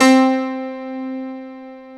Keys (8).wav